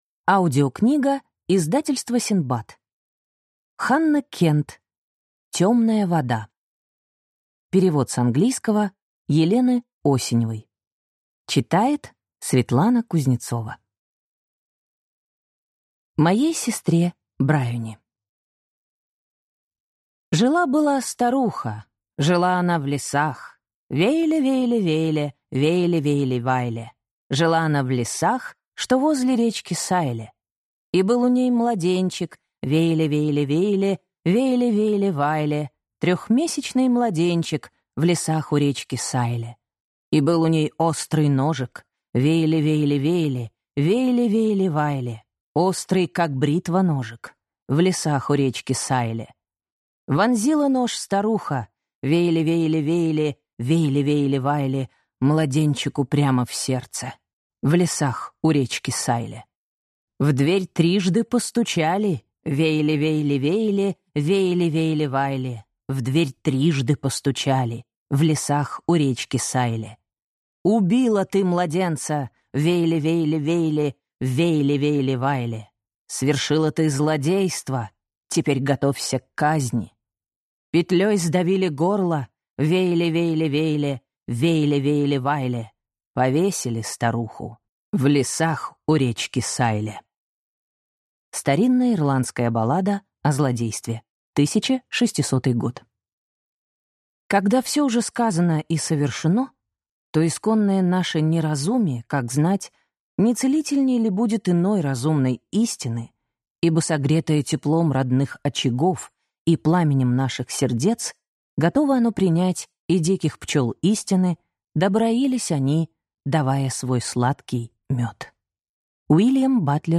Аудиокнига Темная вода - купить, скачать и слушать онлайн | КнигоПоиск